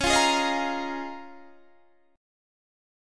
Db6add9 Chord
Listen to Db6add9 strummed